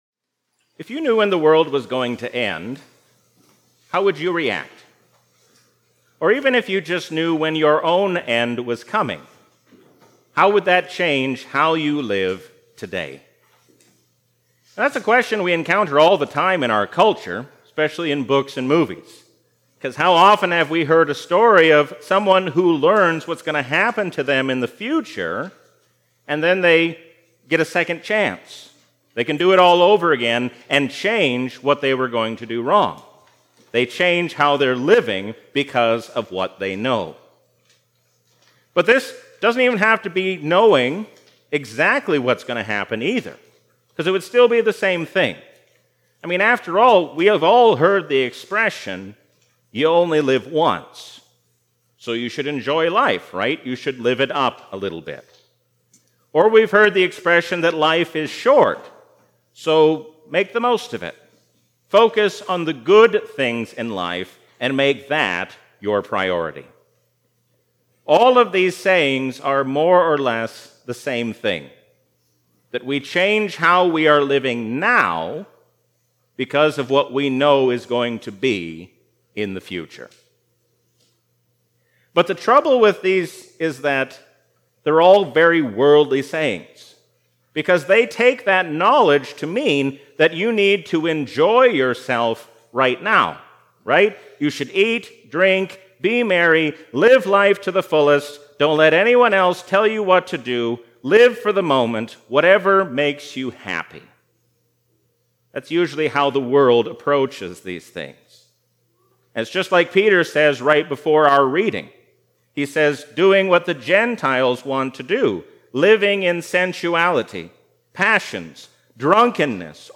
A sermon from the season "Easter 2022."